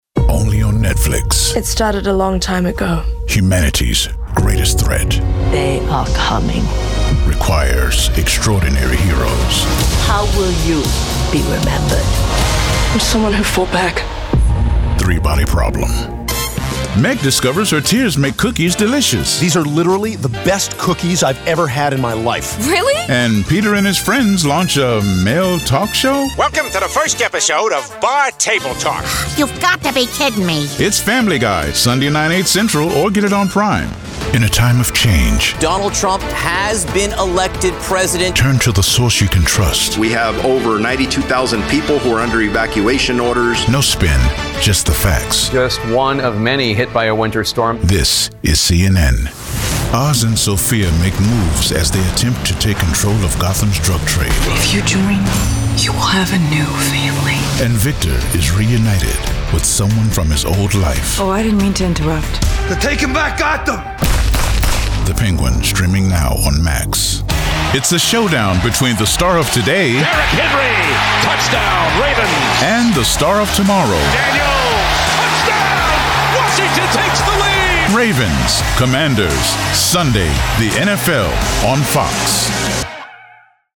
Promo